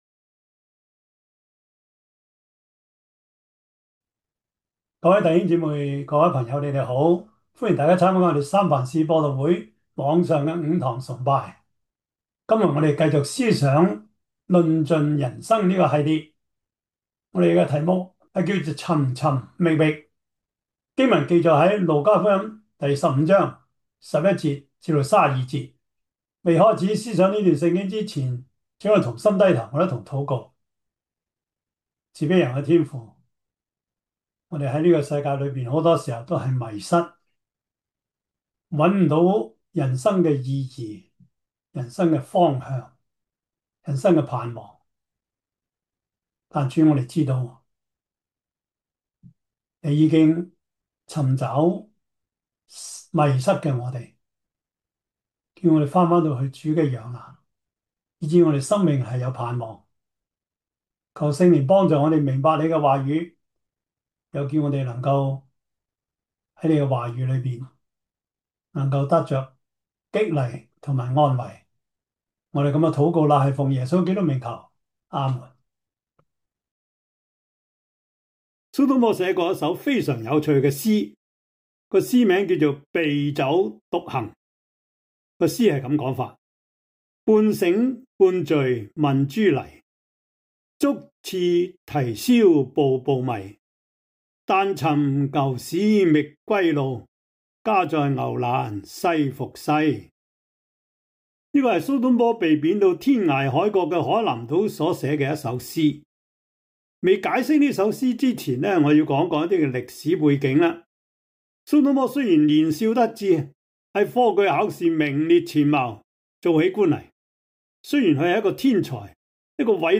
路加福音 15:11-32 Service Type: 主日崇拜 路加福音 15:11-32 Chinese Union Version